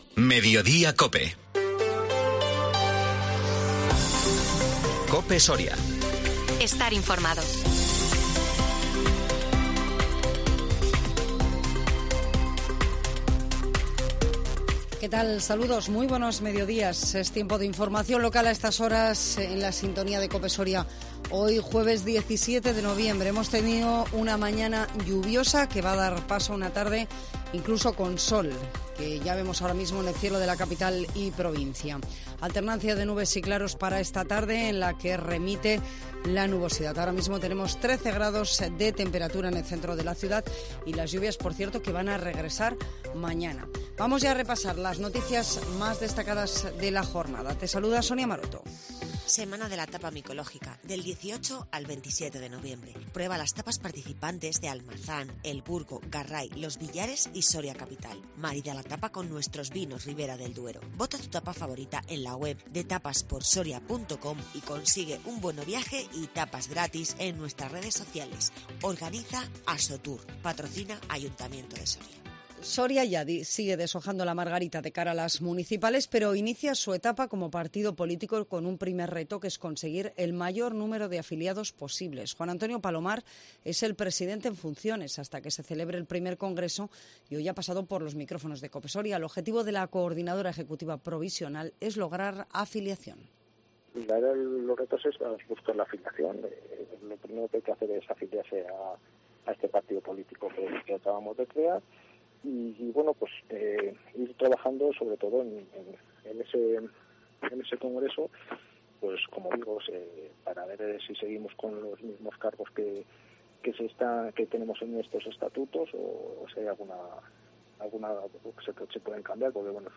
INFORMATIVO MEDIODÍA COPE SORIA 17 NOVIEMBRE 2022